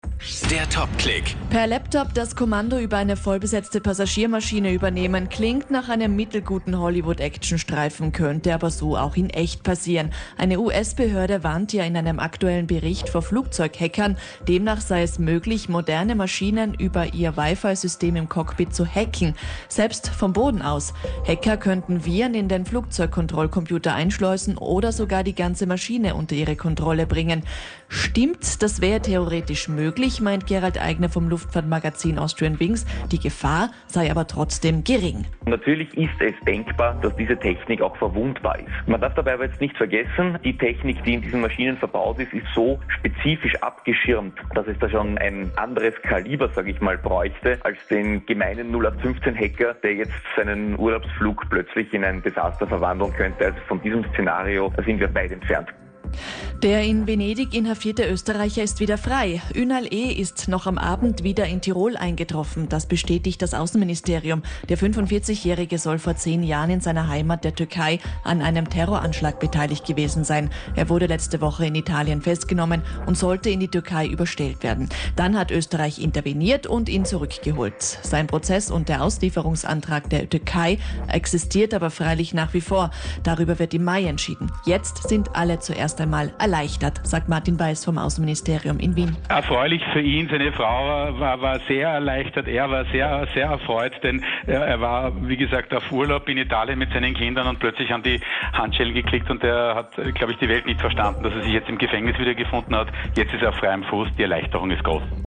Interview mit Kronehit Radio: Sind Hackerangriffe auf Flugzeuge denkbar?
Kronehit_Interview_-_Hackerangriffe_auf_Flugzeug-Infrastruktur.MP3